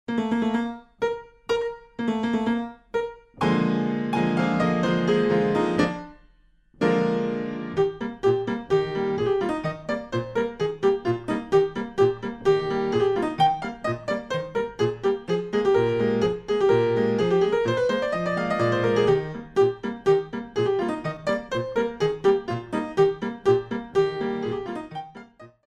Coda